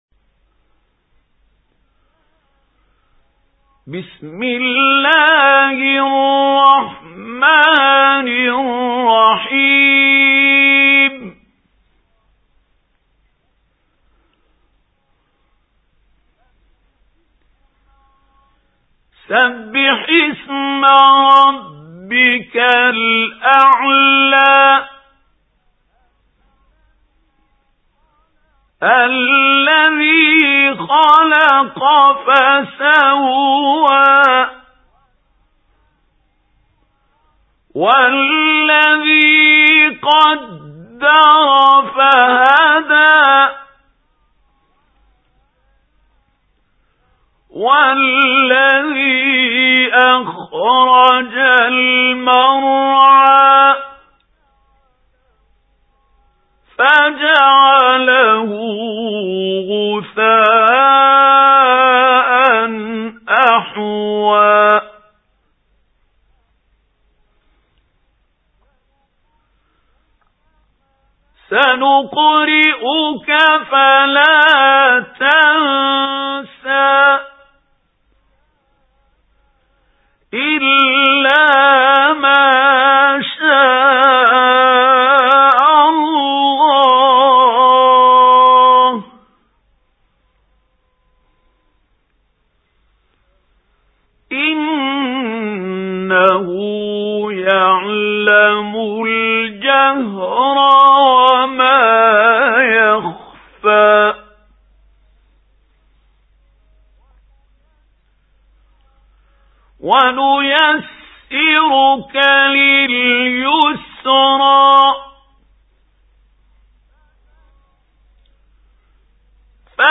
سُورَةُ الأَعۡلَىٰ بصوت الشيخ محمود خليل الحصري